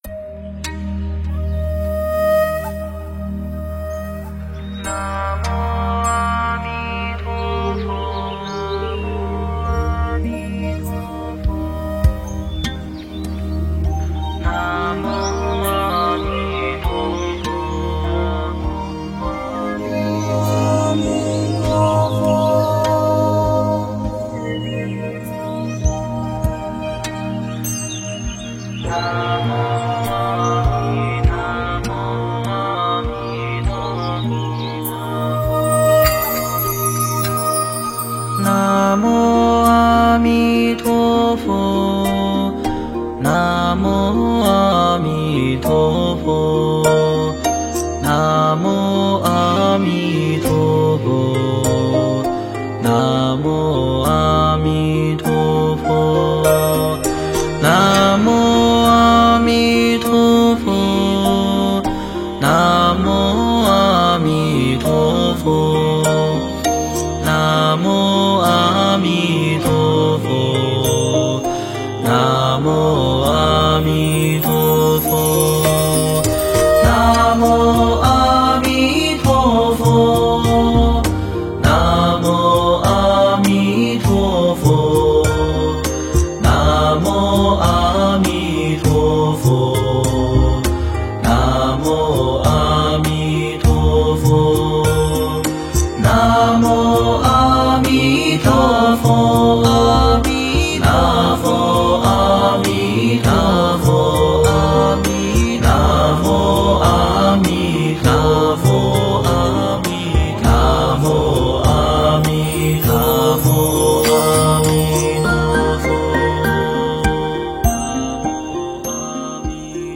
诵经
佛音 诵经 佛教音乐 返回列表 上一篇： 六字真言颂-六字断除六道苦难颂 下一篇： 六字大明咒 相关文章 南无莲池海会佛菩萨--佛典艺术工作坊 南无莲池海会佛菩萨--佛典艺术工作坊...